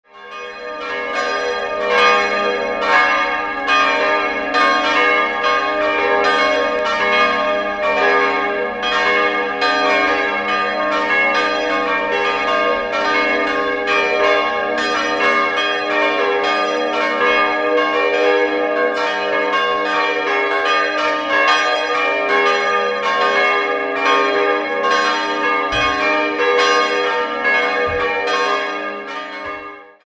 Sie wurde in den Jahren 1906 bis 1908 nach Plänen des Augsburger Architekten Michael Kurz erbaut. 4-stimmiges Gloria-TeDeum-Geläute: as'-b'-des''-es'' Die Glocken as' (497 kg) und es'' (156 kg) wurden 1953 von Friedrich Wilhelm Schilling gegossen.